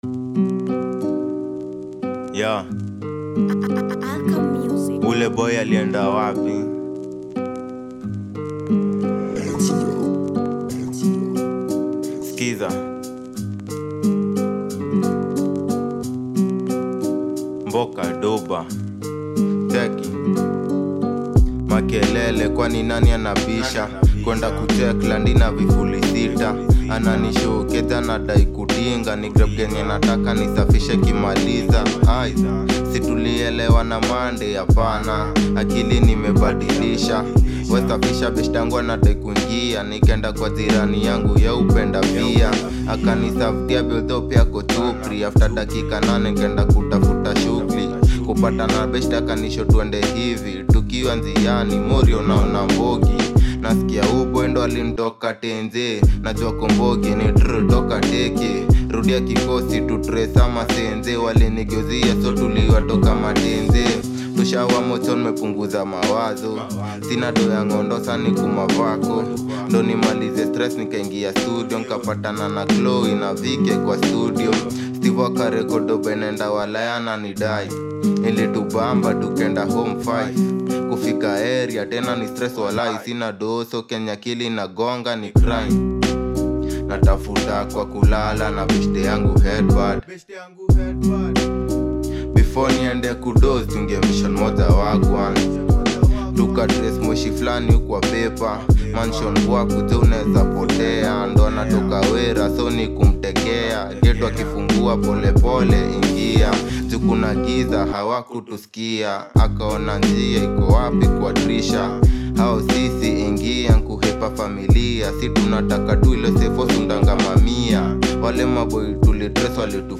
With its soulful rhythm and powerful storytelling